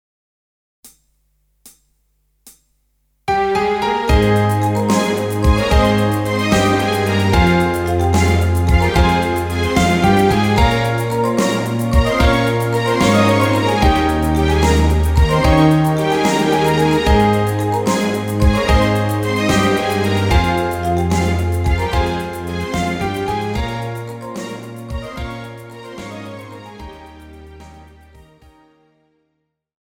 Žánr: Pop
BPM: 73
Key: Ab
MP3 ukázka